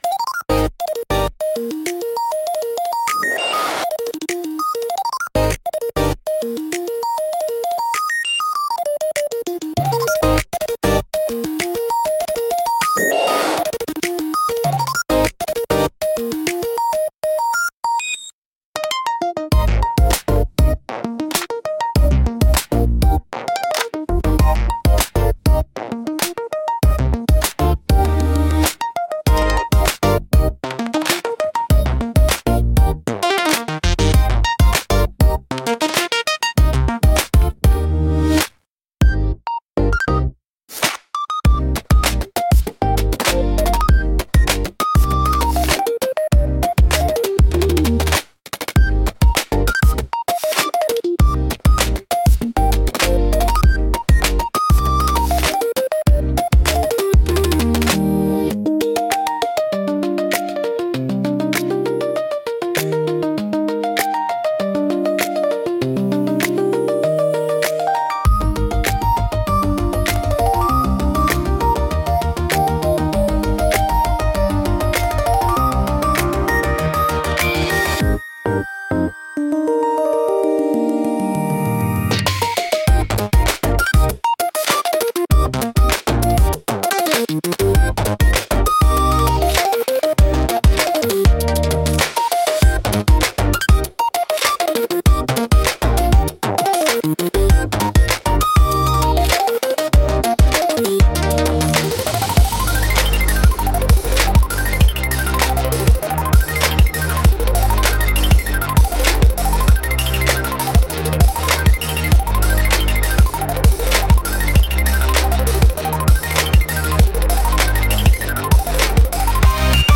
聴く人の感覚を刺激し、緊張感や好奇心を喚起しながら、独自の雰囲気を強調する効果があります。